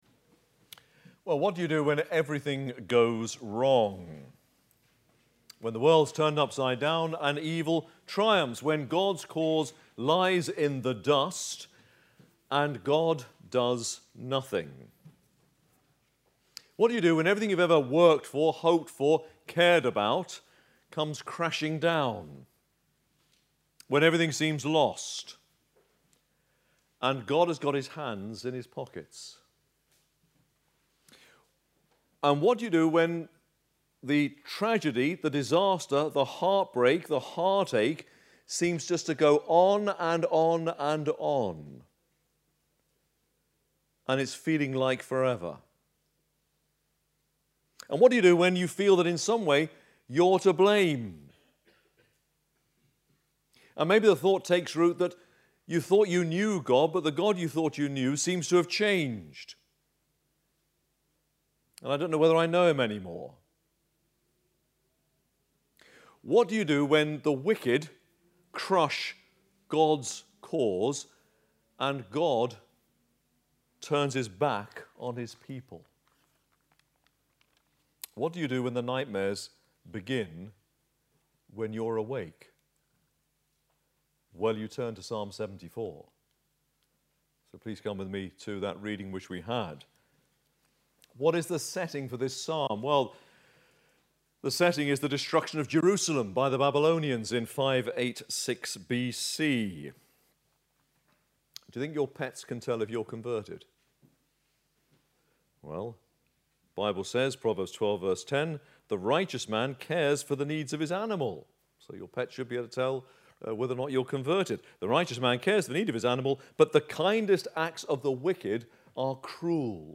APC - Sermons